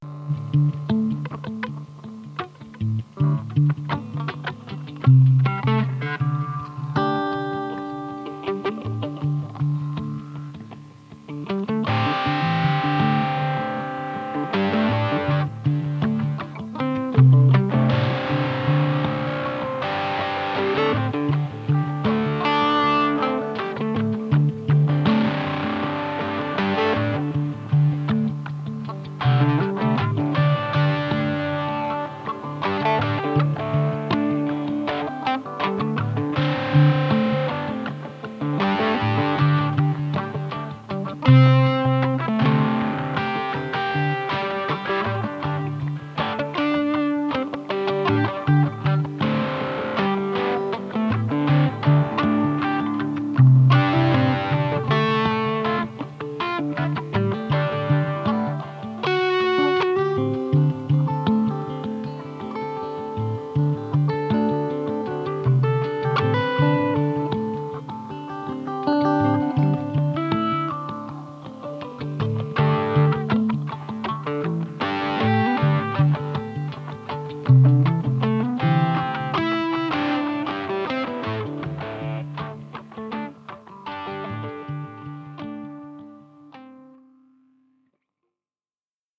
Das heutige Mitbringsel kleiner Fender-Exkursionen in die Höhlenwelt der Sound-Escapes brachte drei kleine Stücke ans Tageslicht, sie heissen: